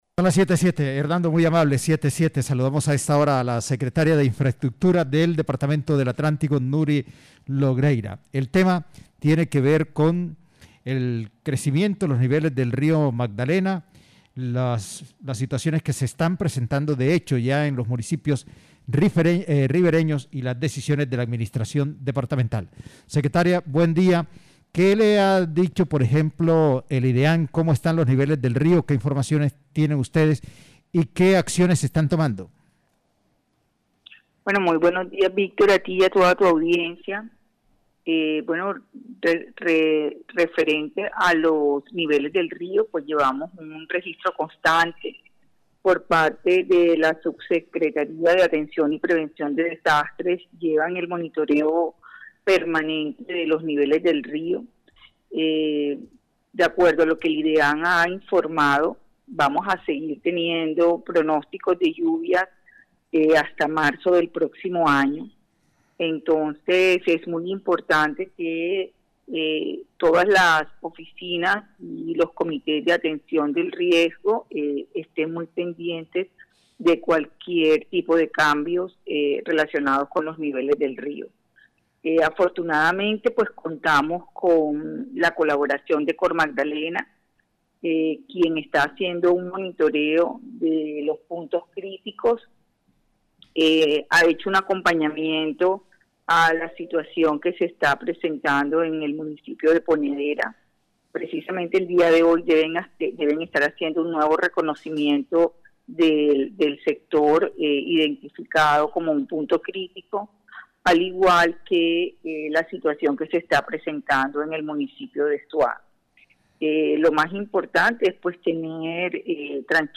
Ante esto, la secretaria de Infraestructura departamental, Nuris Logreira, a través de los micrófonos del Sistema Cardenal, envió un parte de tranquilidad a la población debido a que llevan un registro constante de los niveles del río.